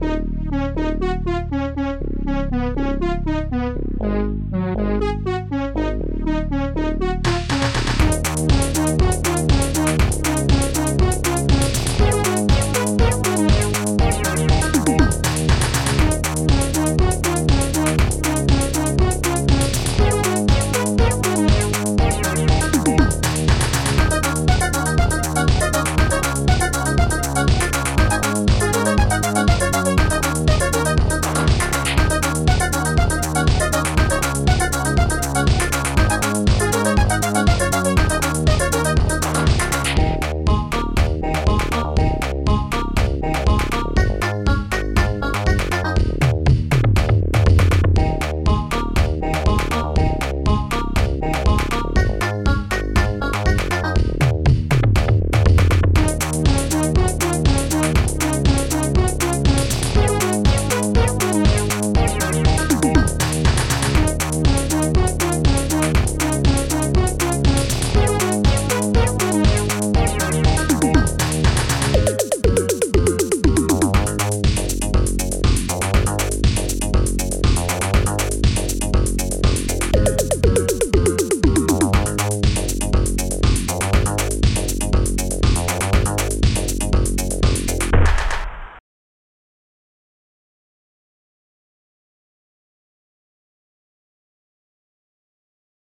SoundTracker Module
Instruments funbass hallbrass hooman hihat2 korgbeau claps1 monobass shaker popsnare2 bassdrum3 dxtom funkbass